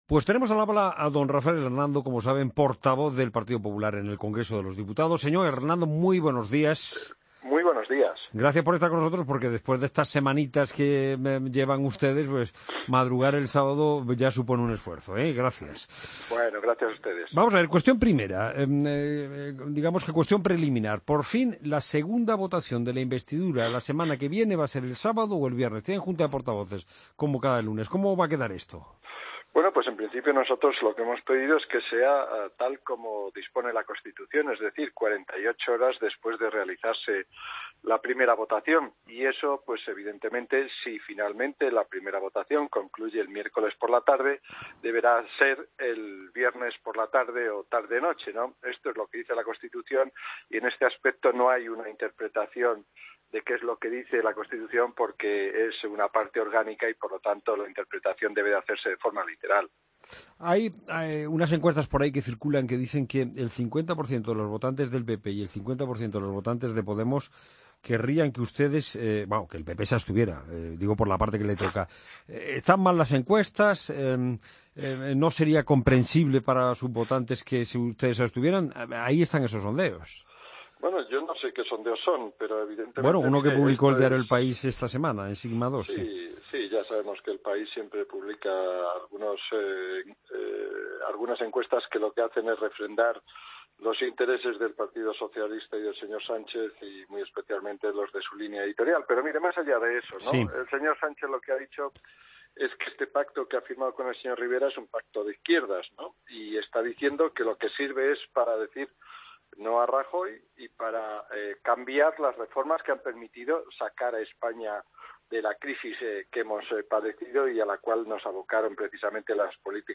AUDIO: Escucha la entrevista a Rafael Hernando, portavoz del PP en el Congreso, el La Mañana Fin de Semana de COPE